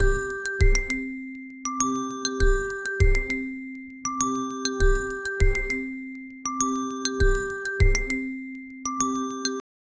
square-and-xilofone-mixed-nidzcosf.wav